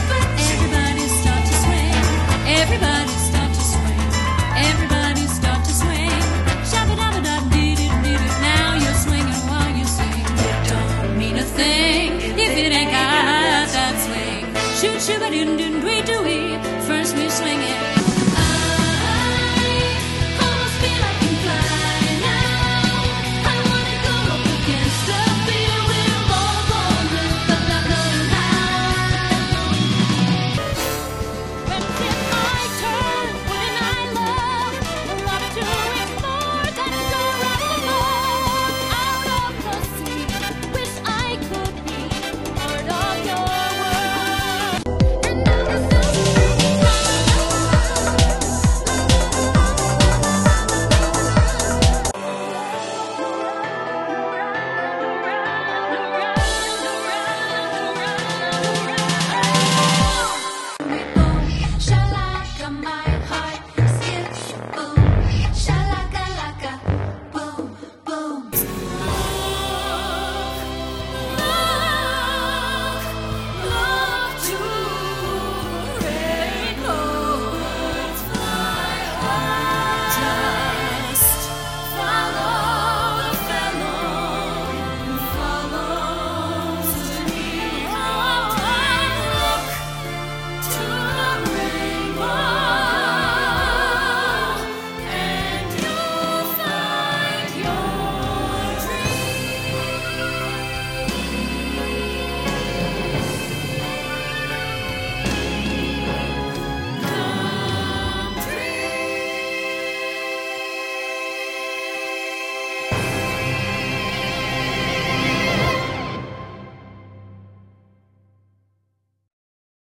Vocal Demo ↓: